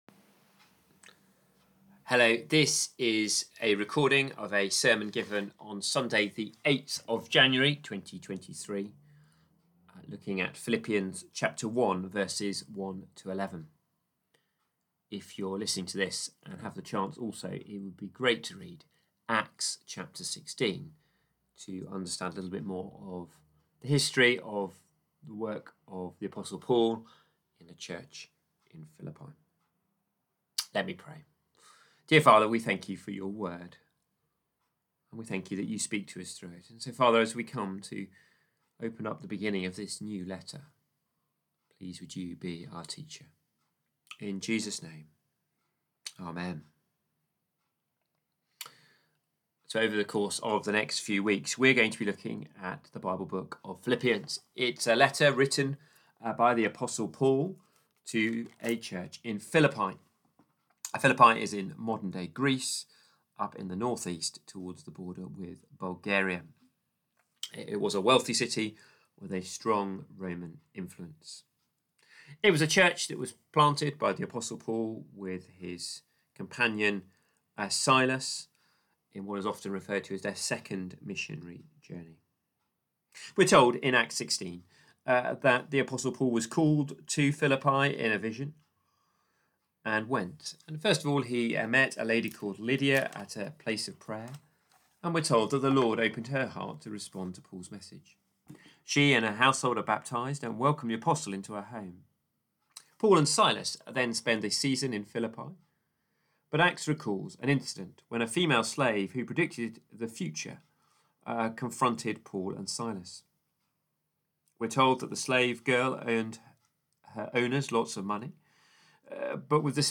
This is a summary of the actual sermon and has been recorded for the benefit of both churches.
Philippians 1:1-11 Service Type: Morning Worship This is a summary of the actual sermon and has been recorded for the benefit of both churches.